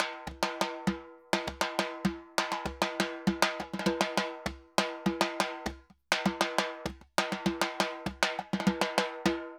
Timba_Baion 100_2.wav